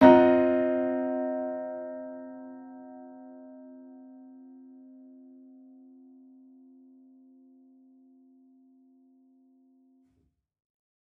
Index of /musicradar/gangster-sting-samples/Chord Hits/Piano
GS_PiChrd-Cmin7+9.wav